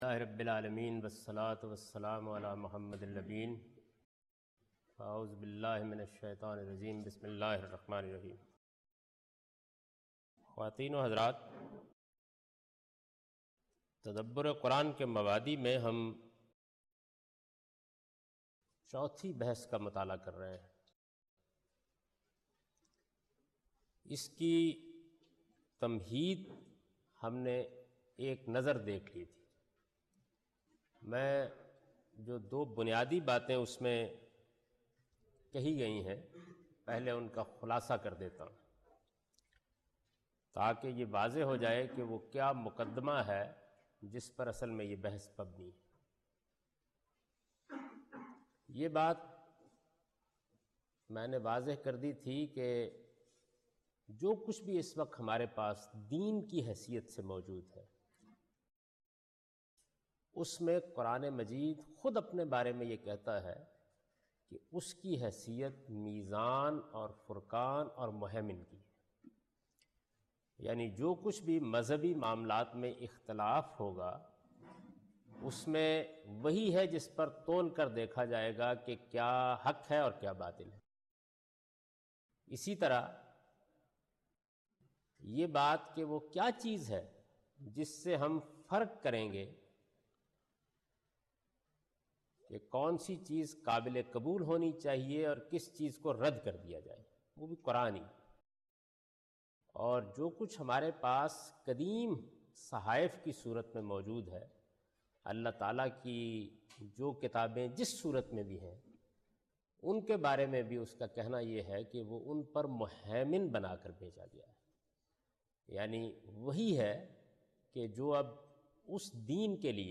A comprehensive course on Islam, wherein Javed Ahmad Ghamidi teaches his book ‘Meezan’.
In this lecture he teaches the importance of final authority of Quran in order to truly understand the book. In the context of final authority definitive meaning of Quranic verse is the topic of discussion. (Lecture no.21 – Recorded on 28th March 2002)